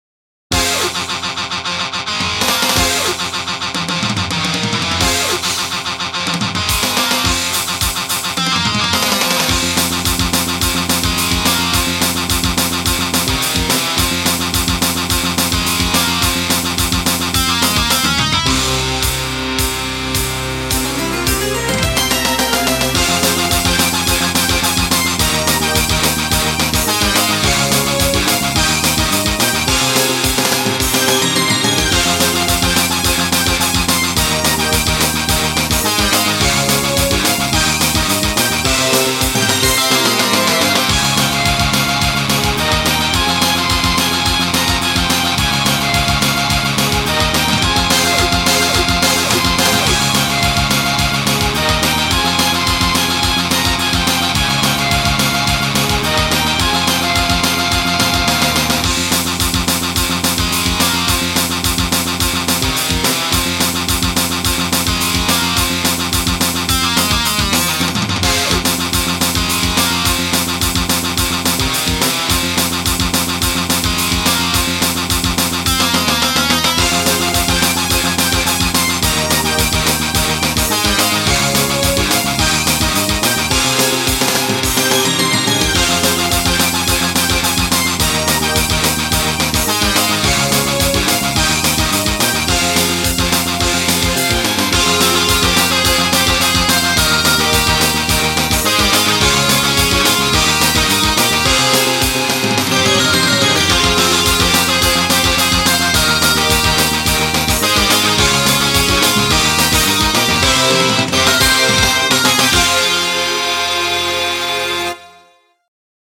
【インスト】
メロディー重視のロック